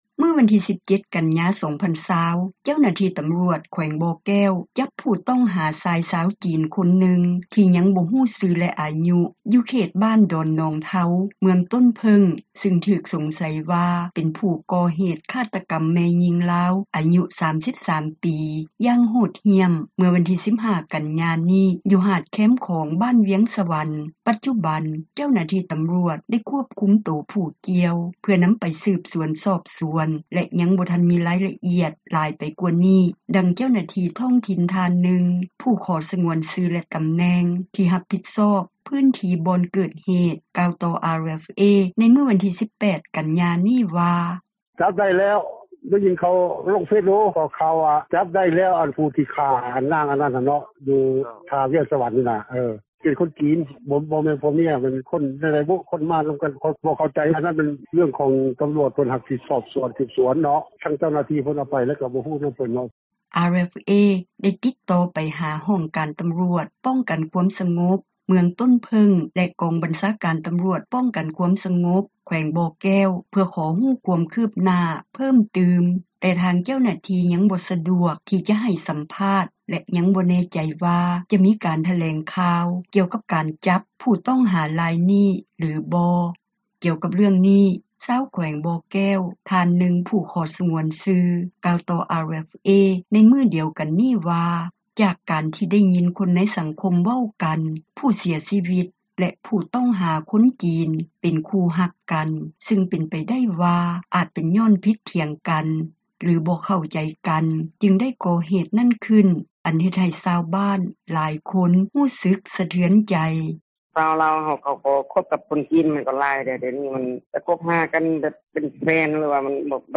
ຈັບຊາຍຊາວຈີນ ຖືກສົງໃສ ຄາຕກັມ ແມ່ຍິງລາວ — ຂ່າວລາວ ວິທຍຸເອເຊັຽເສຣີ ພາສາລາວ